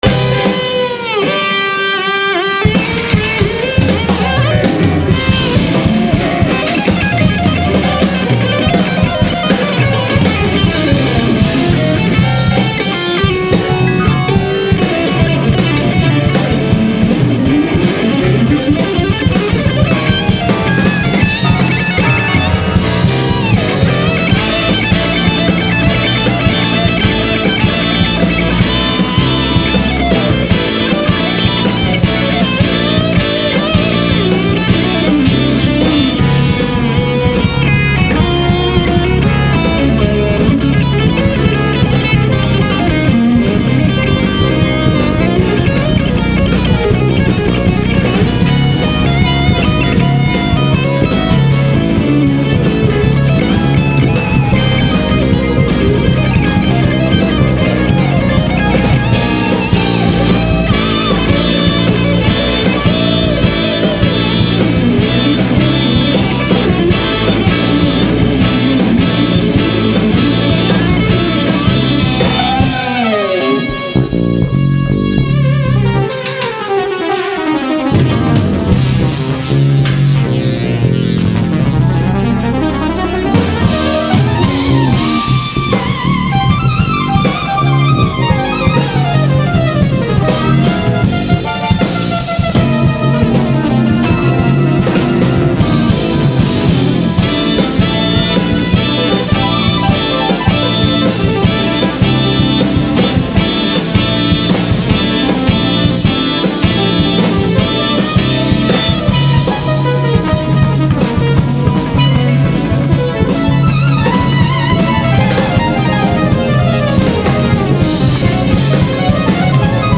steelpan